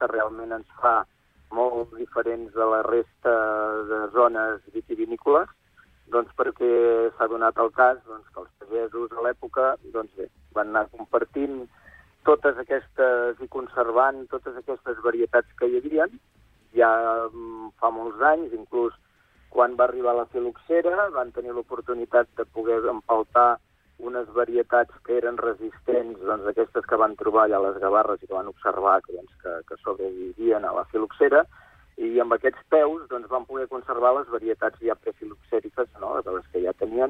En una entrevista concedida al programa Supermatí